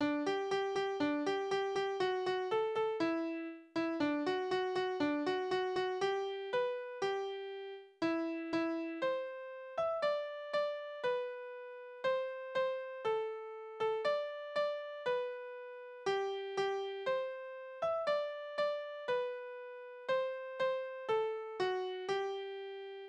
Kinderlieder: Bub und Spinne
Tonart: G-Dur
Taktart: 2/4
Tonumfang: große None
Besetzung: vokal